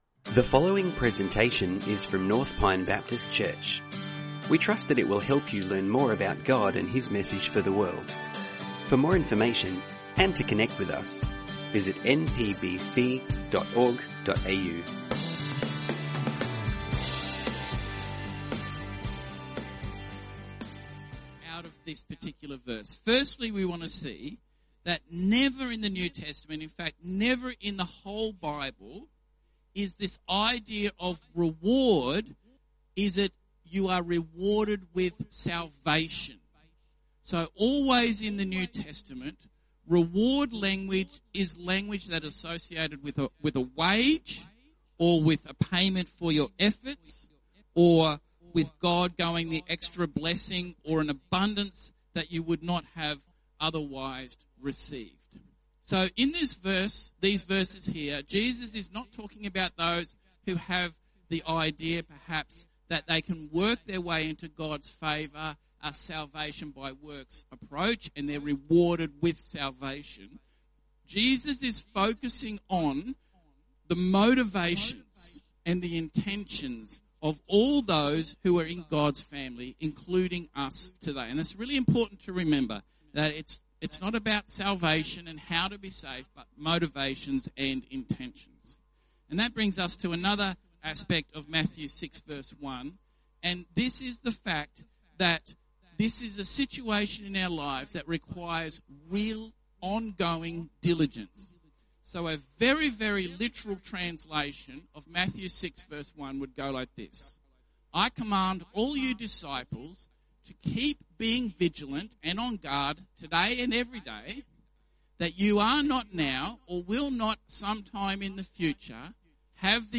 Sermons | North Pine Baptist Church